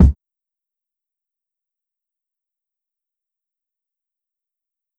Kick